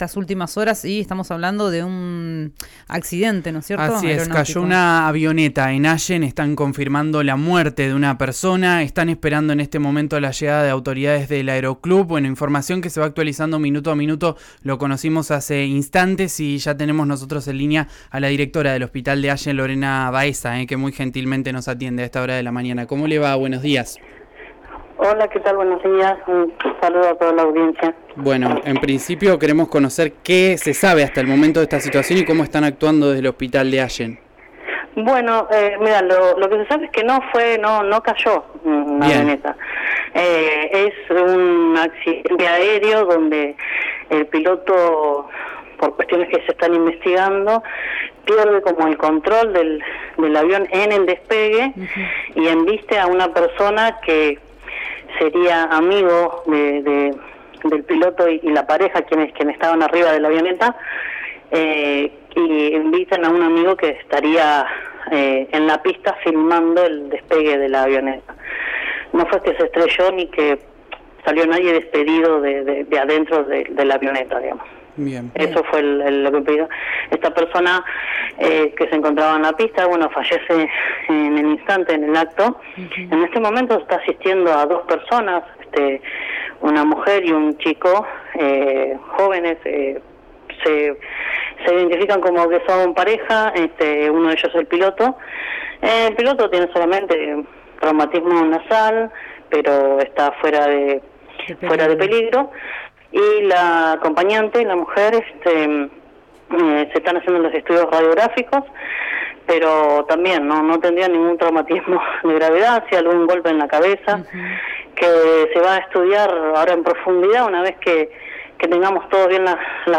en comunicación con RÍO NEGRO RADIO indicó que el accidente fatal tuvo lugar de la siguiente forma: